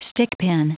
Prononciation du mot stickpin en anglais (fichier audio)
Prononciation du mot : stickpin